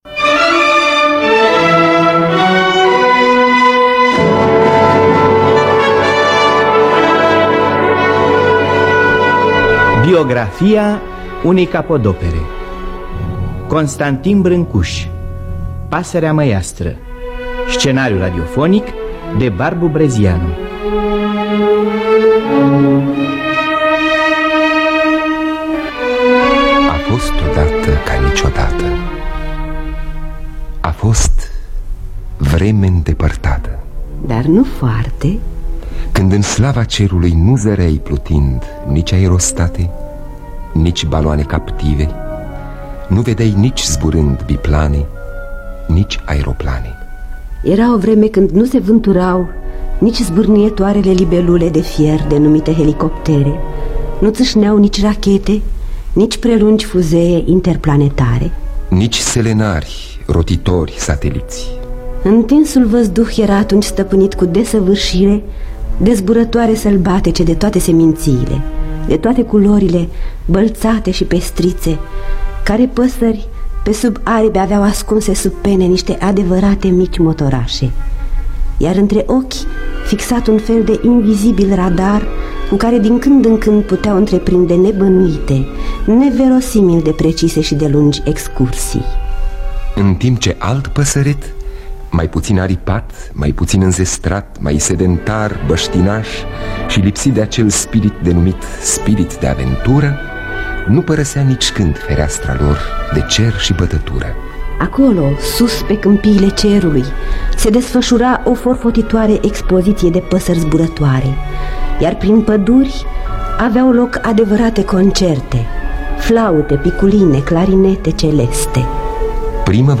Scenariu radiofonic de Barbu Brezianu.